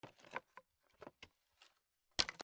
古びた木片 小さな物音
『パ』